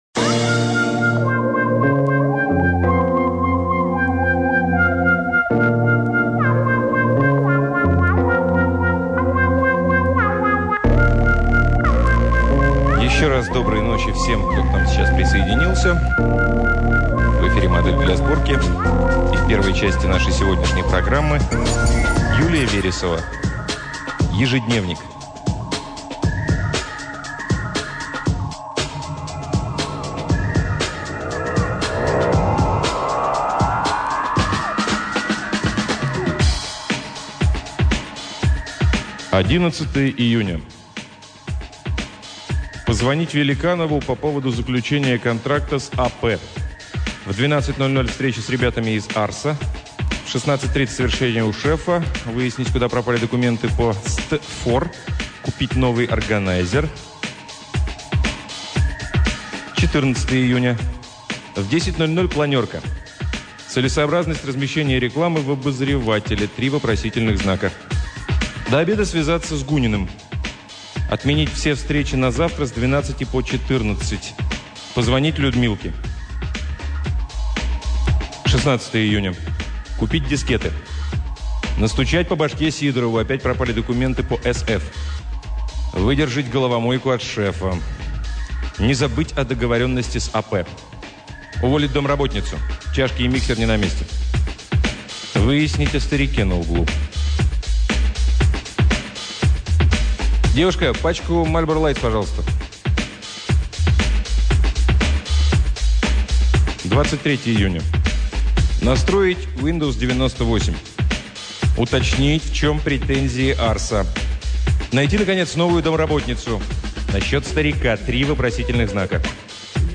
Аудиокнига Юлия Вересова — Ежедневник